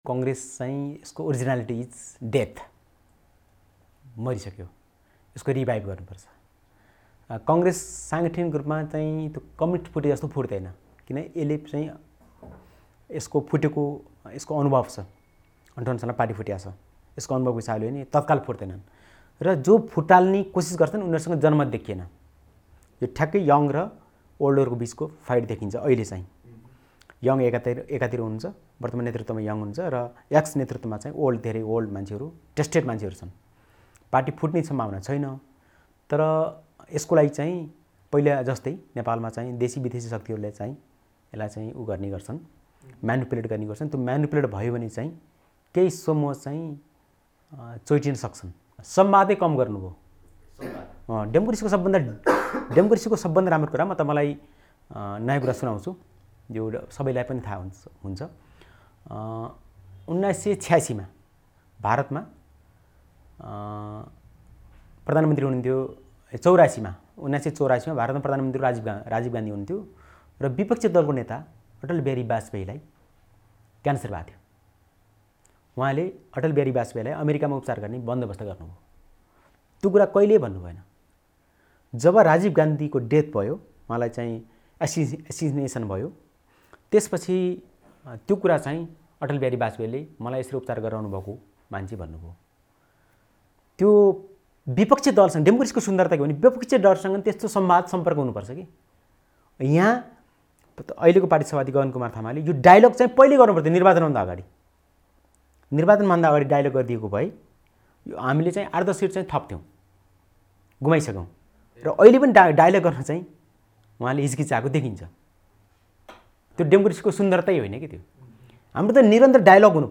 विशेष संवादमा उनले कांग्रेसभित्र अब युवा एकातिर र पाका पुस्ताका नेताहरु अर्कोतिर हुने बताए ।